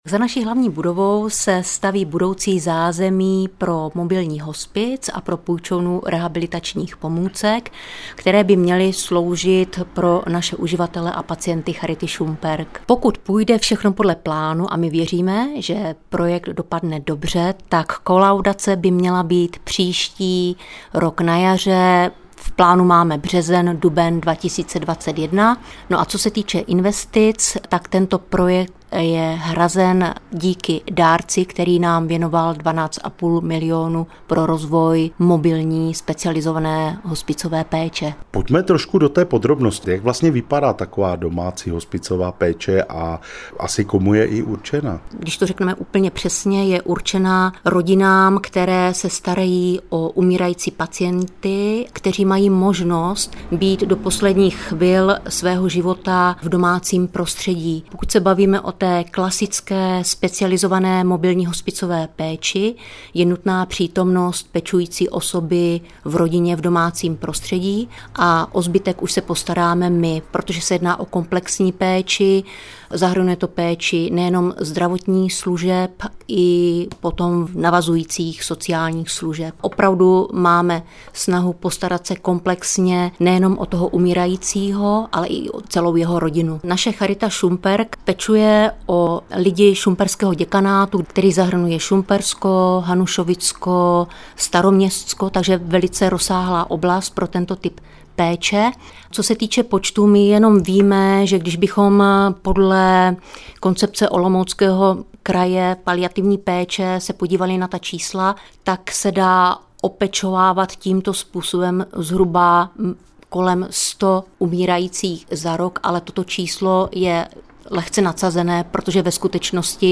Reportáž najdete v čase 11:42.